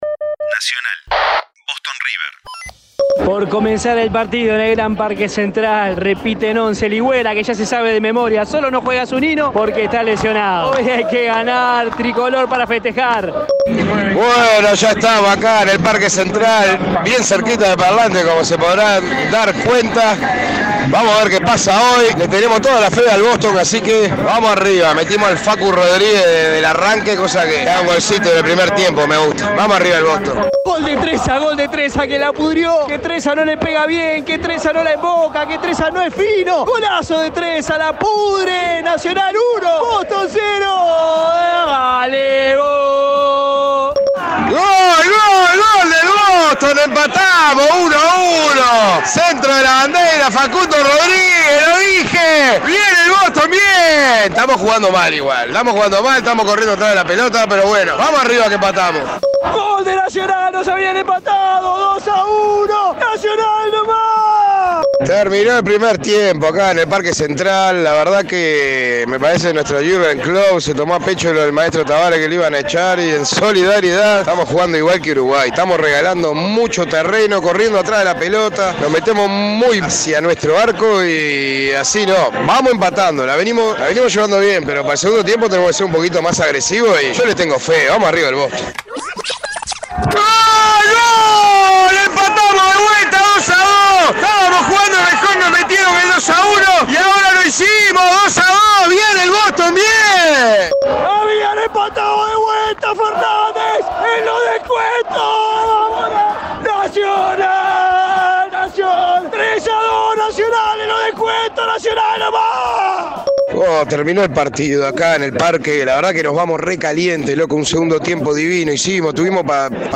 Los partidos de la sexta fecha del Torneo Clausura del fútbol uruguayo vistos y comentados desde las tribunas.